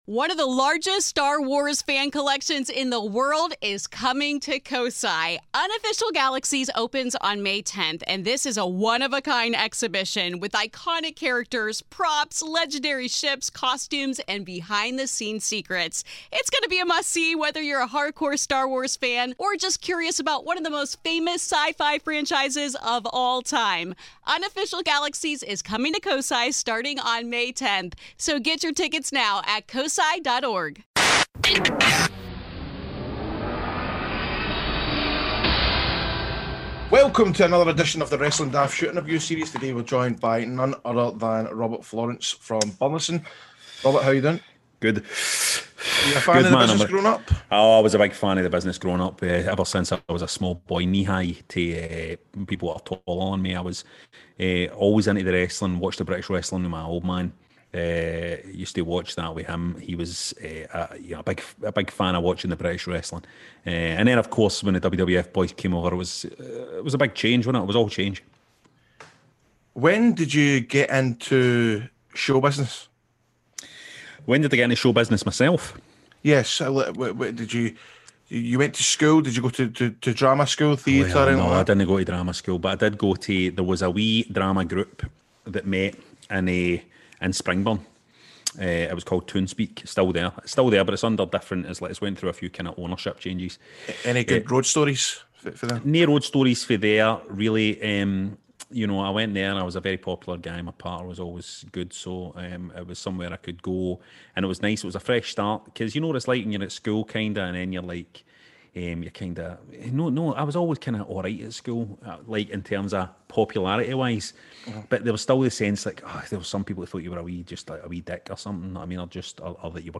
RF Shoot Interview